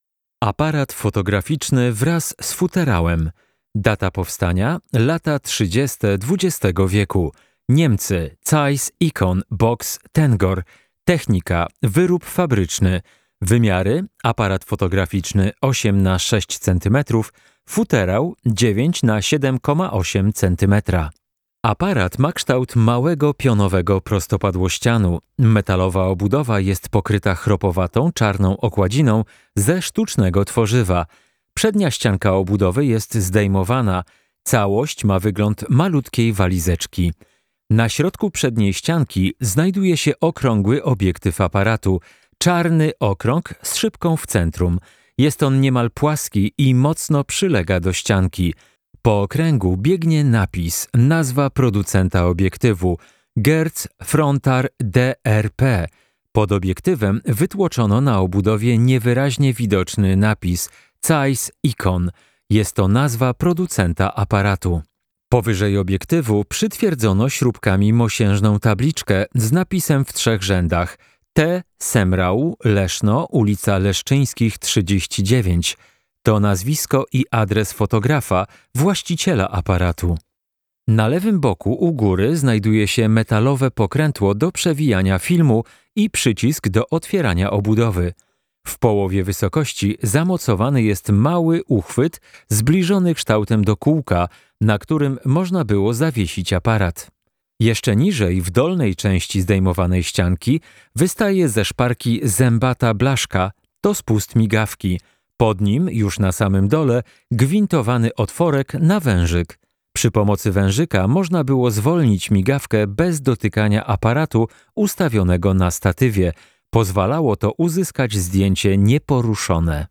Audiodeskrypcja -